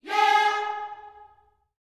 YEAH G#4D.wav